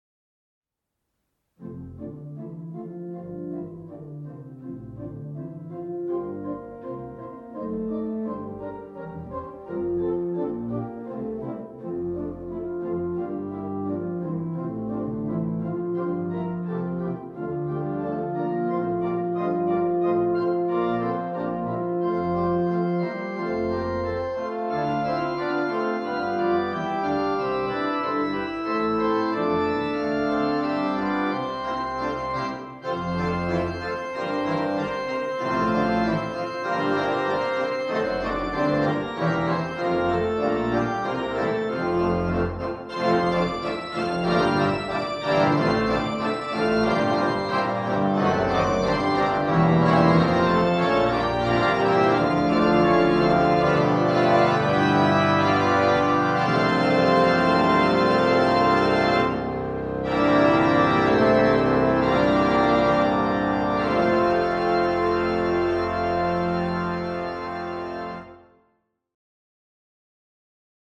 Deze (niet professionele) opnames geven een indruk van de sfeer en mogelijkheden van verschillende orgels op diverse locaties.
Ecclesia-T-255-generaal-crescendo-Engels-Rom-Sprang-def.mp3